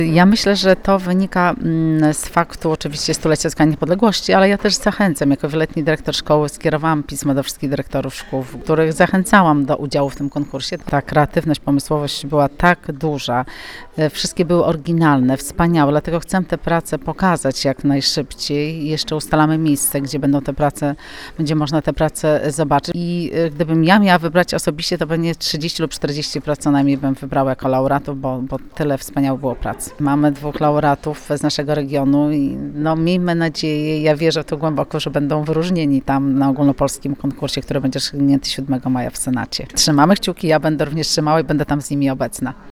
– To był ciężki wybór, ale cieszy fakt, że z naszego regionu wpłynęło najwięcej prac w Polsce – mówi senator Małgorzata Kopiczko.